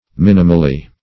minimally - definition of minimally - synonyms, pronunciation, spelling from Free Dictionary